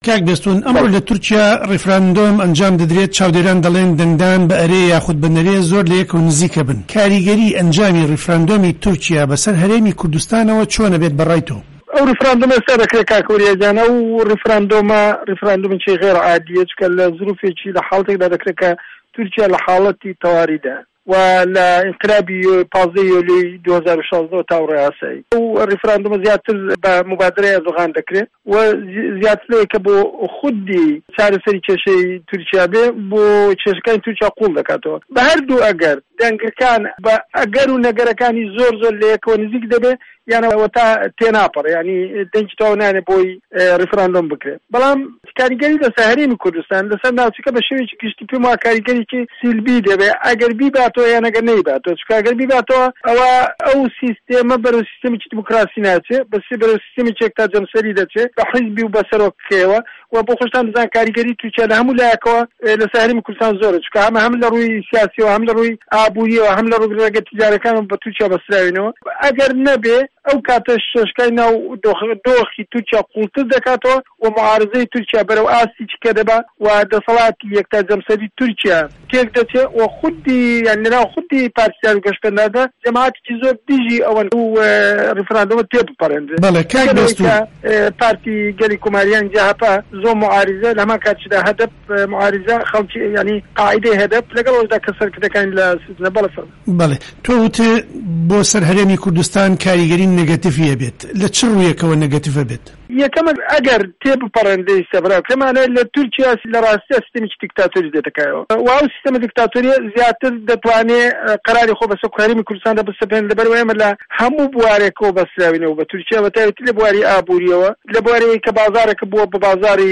تورکیا - گفتوگۆکان